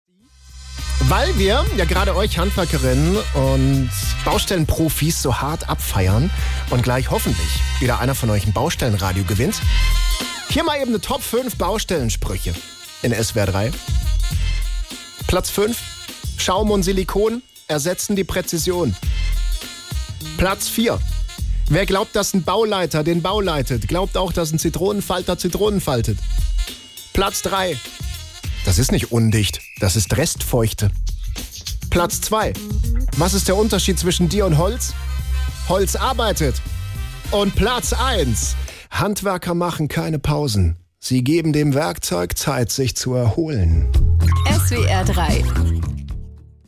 Lustige Sprüche von der Baustelle: Hier ist das Best-of aus dem Radio